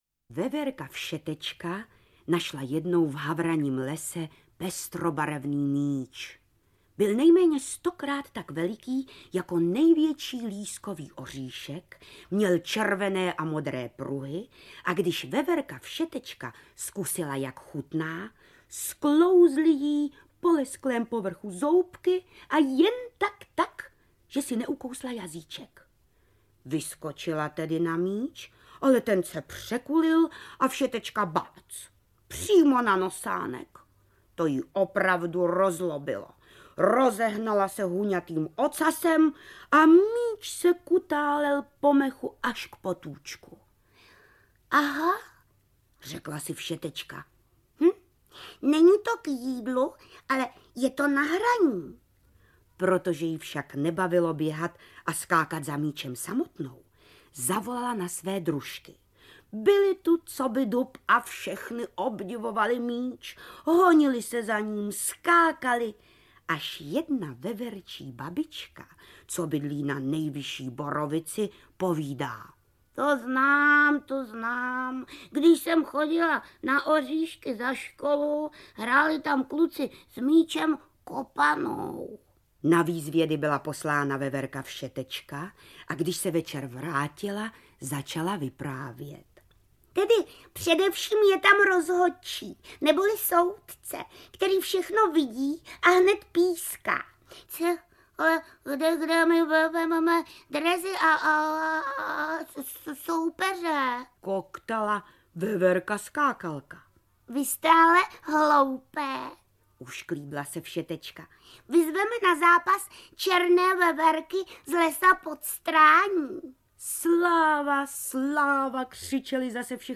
Audiokniha
Nejnovější kompilaci pohádkových titulů naší řady "Pohádky s..." jsme vybrali z tvorby další letošní jubilantky JIŘINY BOHDALOVÉ (*03.05.1931), významné vypravěčky dětských pohádek, charizmatické herečky a moderátorky s nezaměnitelným projevem a hlasem. Nový titul určený pouze pro digitální obchody obsahuje pohádky Linda, kočka zahradní, O strašlivém tygru Bedínkovi, Pohádku o veverčí jedenáctce a nemohou chybět ani ukázky pohádek z proslulých večerníčků Jiřiny Bohdalové - o vodníku Česílkovi, O Křemílkovi a Vochomůrkovi či z Pohádek ovčí babičky.